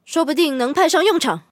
LT-35获得资源语音.OGG